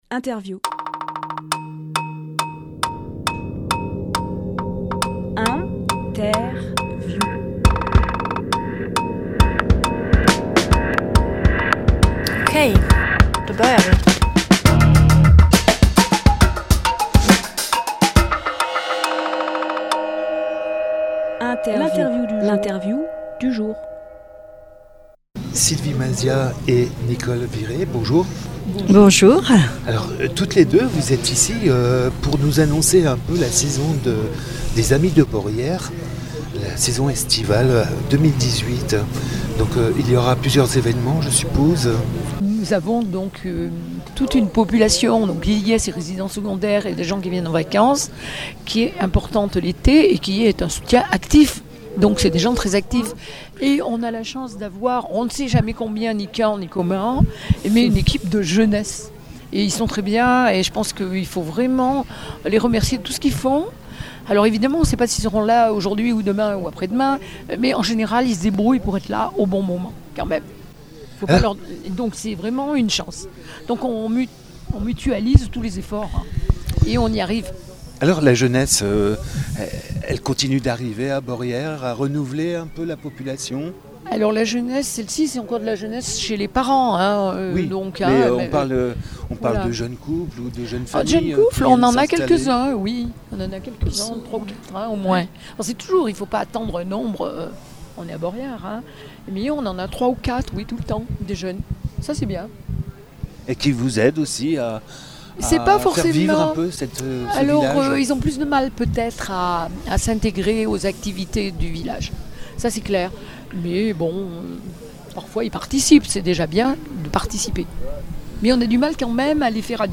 Emission - Interview La saison culturelle et estivale par les Amis de Beaurières Publié le 7 juin 2018 Partager sur…